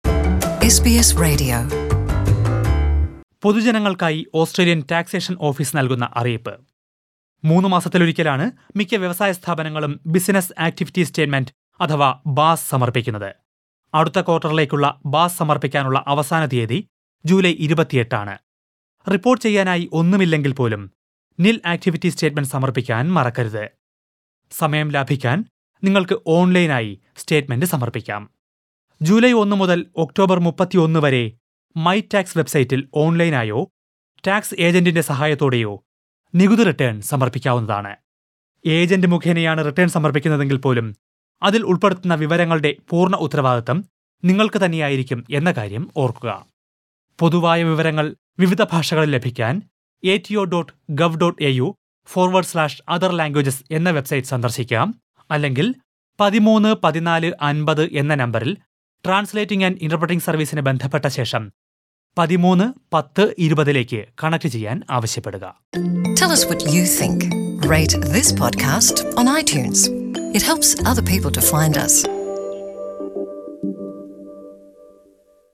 പൊതുജനങ്ങള്‍ക്കായി ഓസ്‌ട്രേലിയന്‍ ടാക്‌സേഷന്‍ ഓഫീസ് നല്‍കുന്ന അറിയിപ്പ്‌